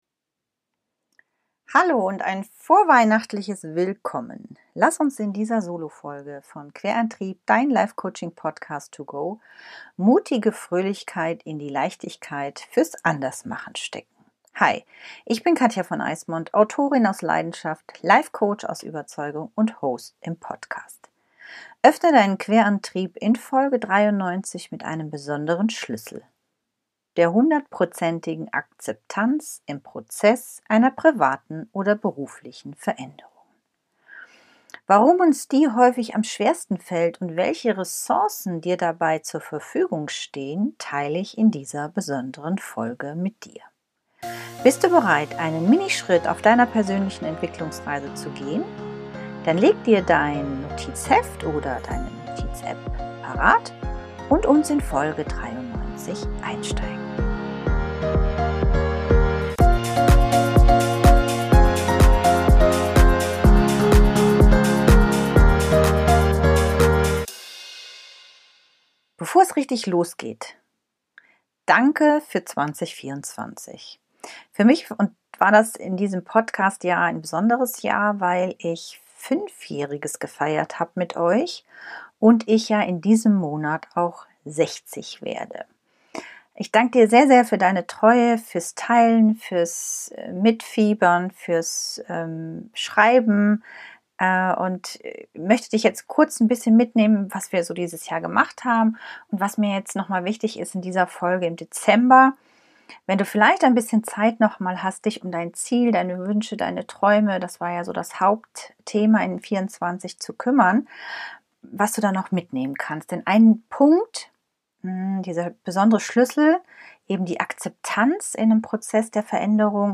Hol dir in der Dezember Folge Inspiration wie du den Punkt überwindest, um da anzukommen, wo du hinwillst. Was das mit deinem inneren Kind, weisen Ich, Ressourcen-Rucksack und dem Glück der schlechten Erfahrungen zu tun hat, teile ich in dieser besonderen Solofolge mit dir.